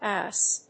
/úːz(米国英語)/